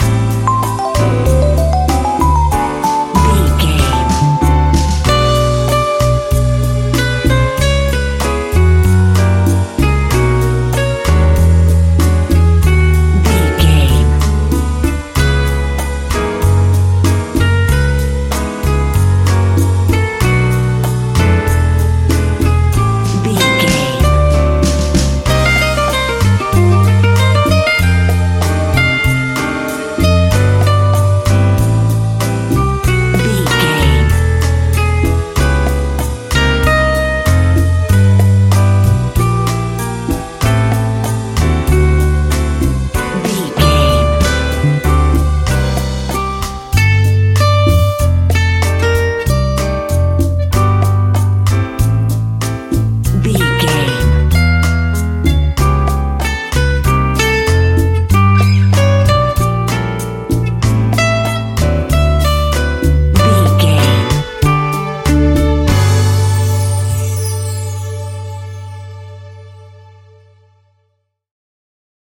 An exotic and colorful piece of Espanic and Latin music.
Ionian/Major
DOES THIS CLIP CONTAINS LYRICS OR HUMAN VOICE?
maracas
percussion spanish guitar
latin guitar